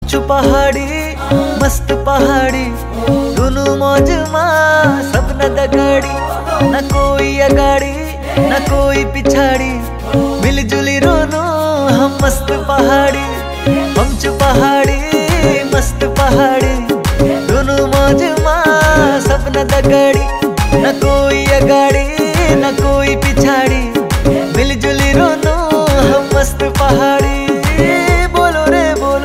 a Pahari song